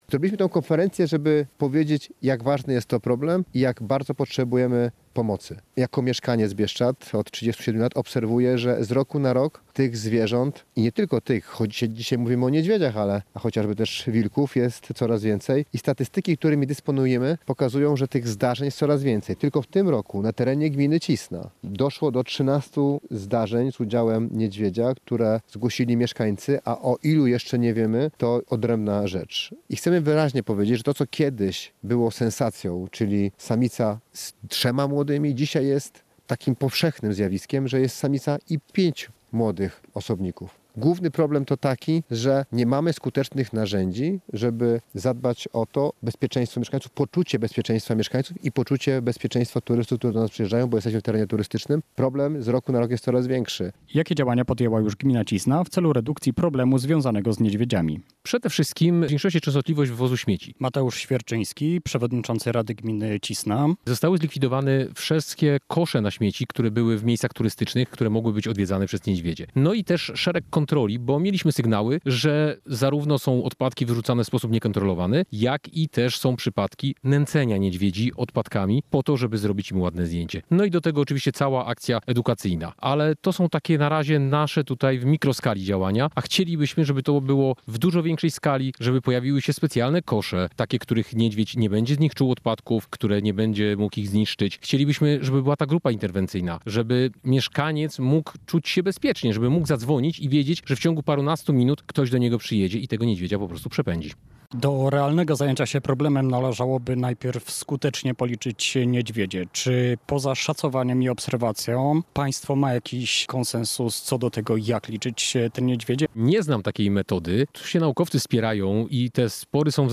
Poszkodowany trafił do szpitala • Relacje reporterskie • Polskie Radio Rzeszów